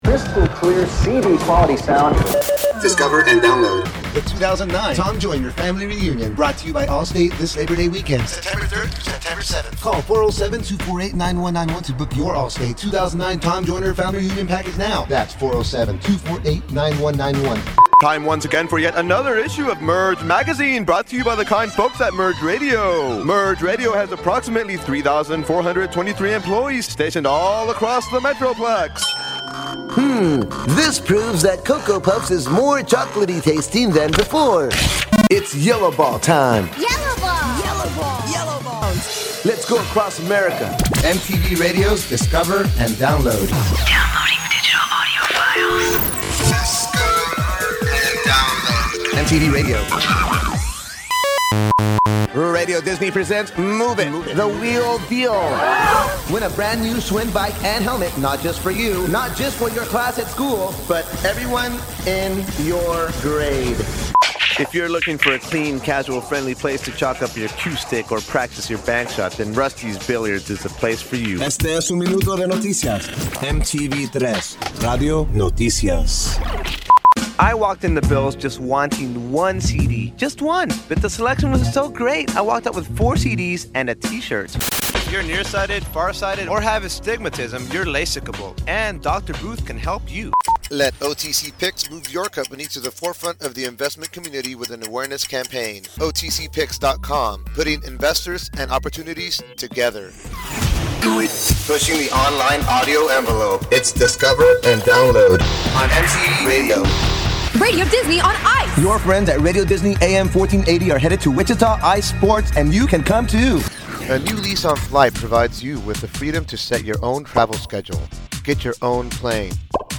This is a sampling of content that I wrote, voiced and produced - an assortment of commercials, promos and station imaging.